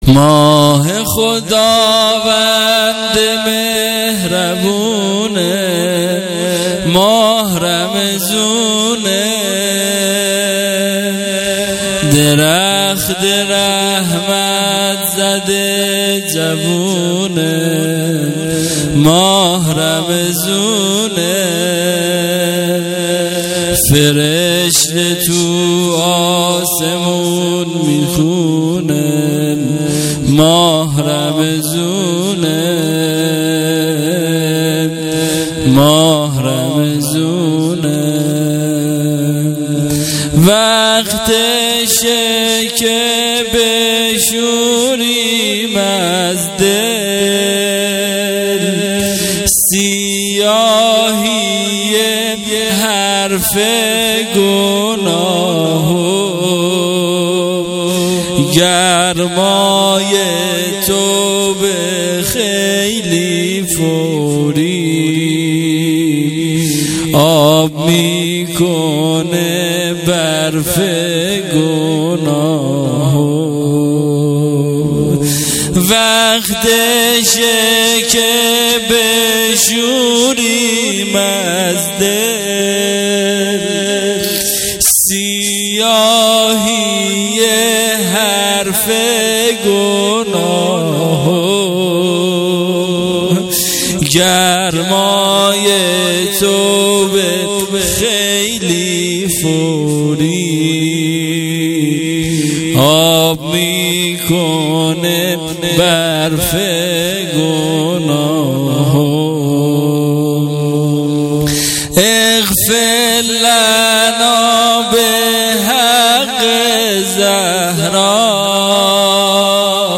عزای وفات حضرت خدیجه س ۹۸